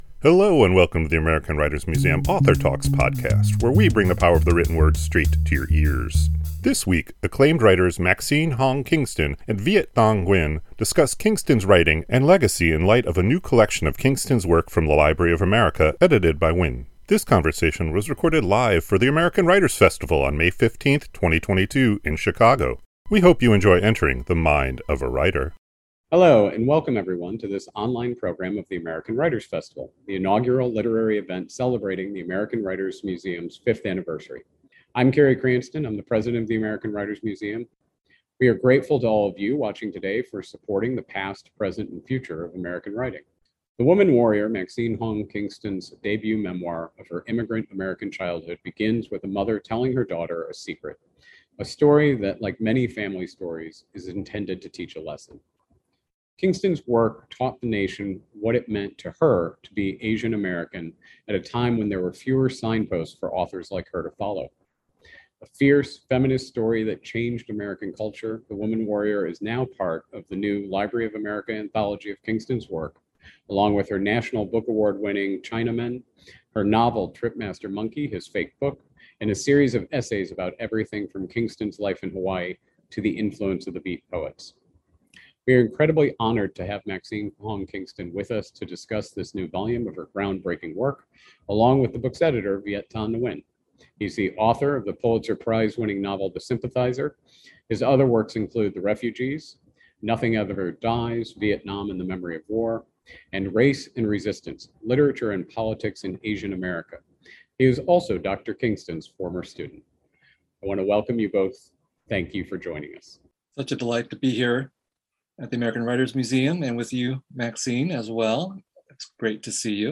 This conversation was recorded live for the American Writers Festival on May 15, 2022 in Chicago.